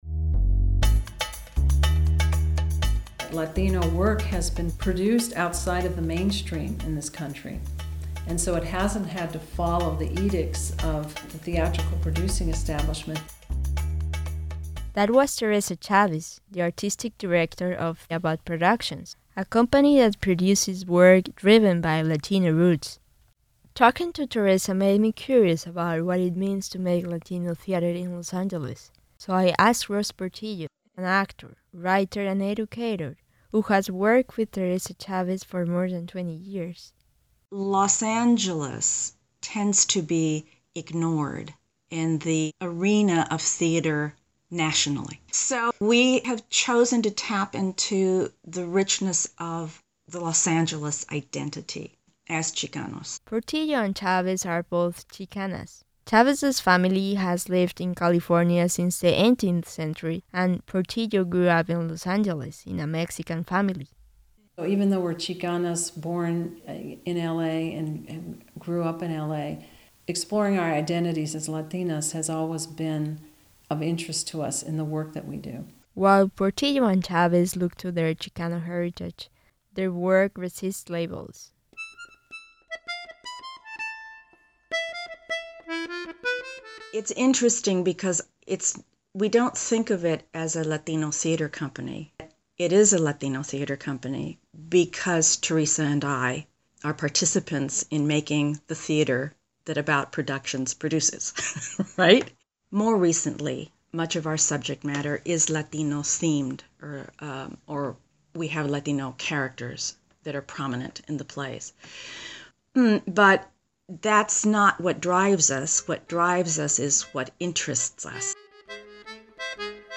In the hills of Silver Lake